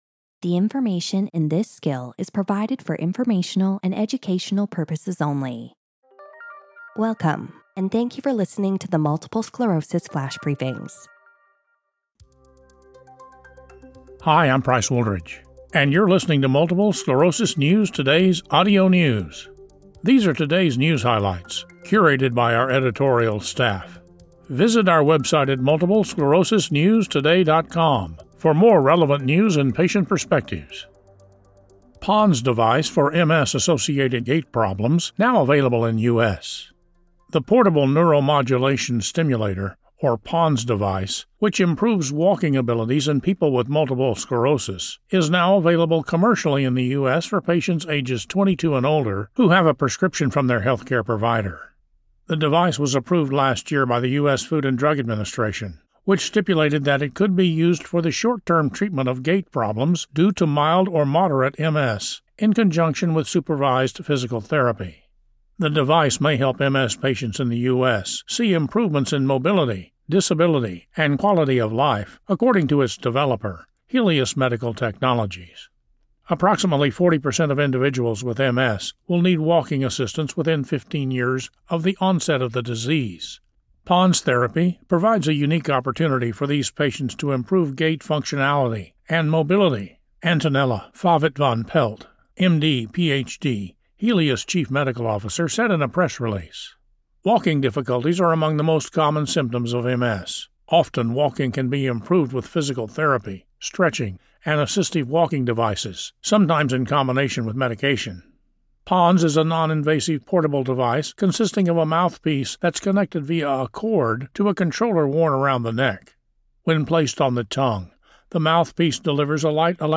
reads an article about the portable neuromodulation stimulator (PoNS) device that was approved by the FDA last year to help treat multiple sclerosis gait problems.